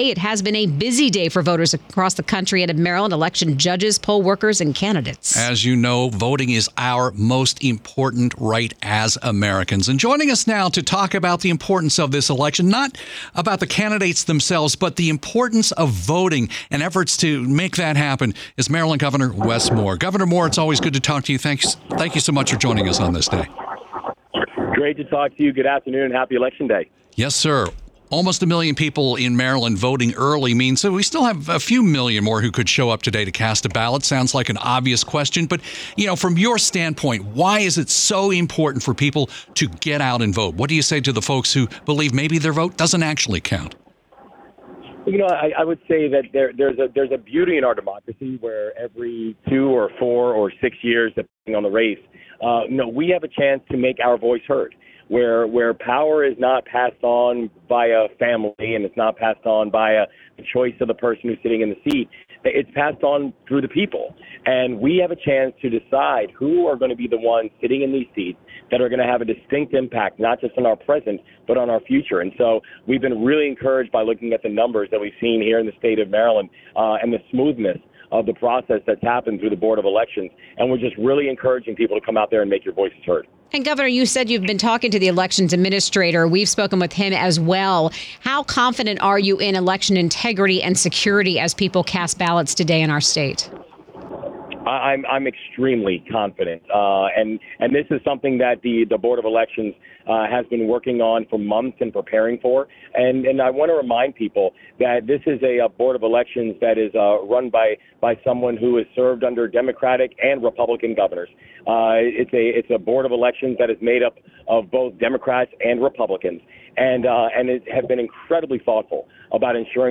wes-moore-live-403.wav